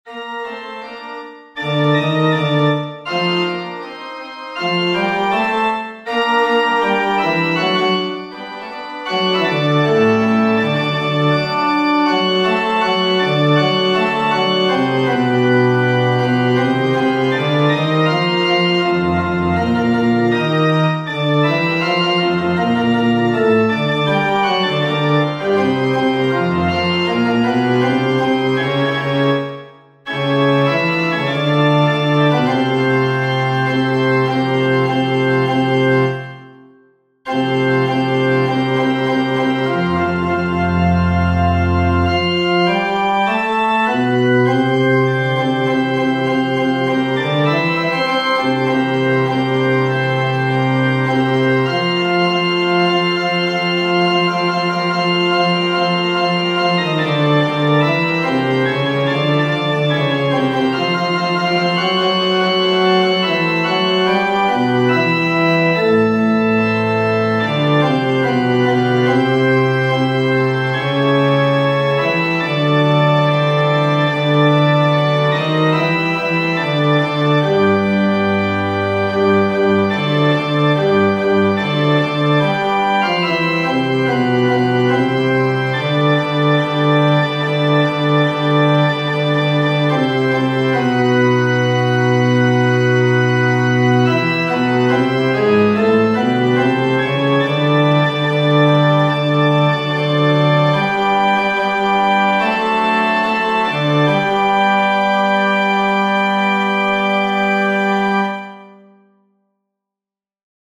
FF:HV_15b Collegium musicum - mužský sbor
Nase_pisen-bas.mp3